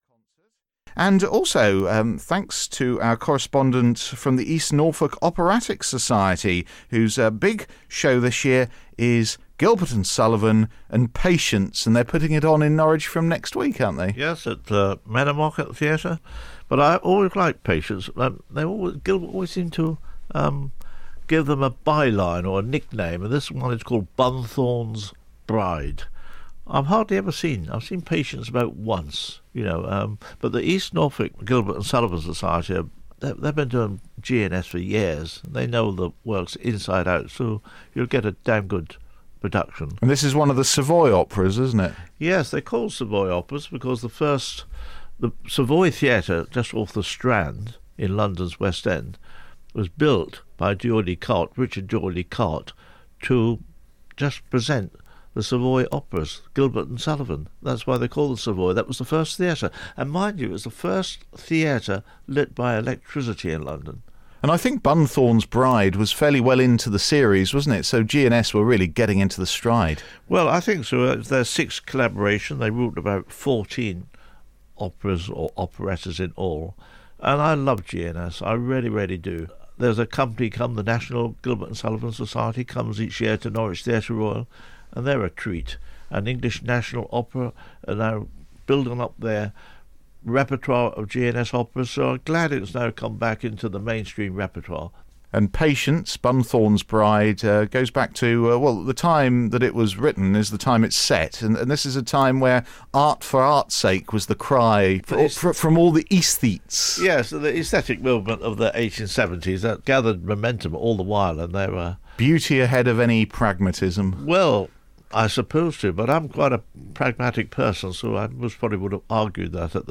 Publicity Trailer broadcast on BBC Radio Norfolk on 4 May 2018